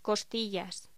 Locución: Costillas
voz